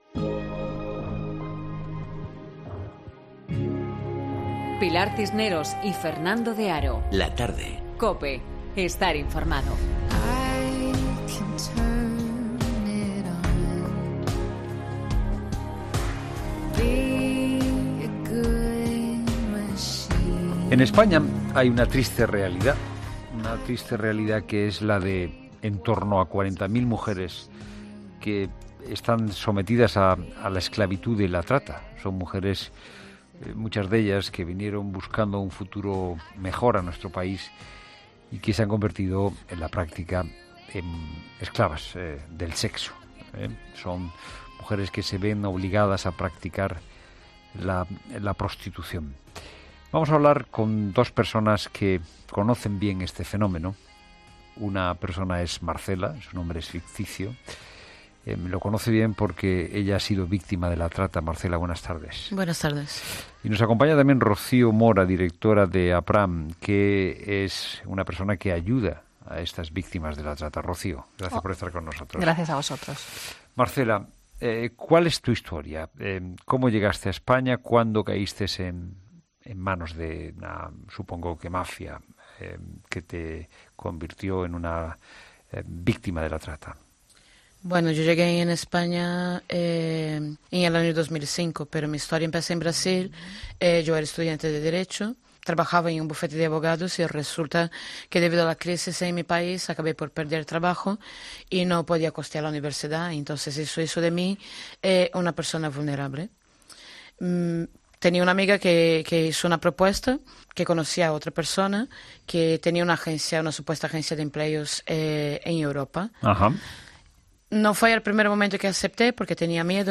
En La Tarde hemos escuchado el testimonio de alguien que ha sido engañada, trasladada a España, obligada a ejercer la prostitución y ha conseguido salir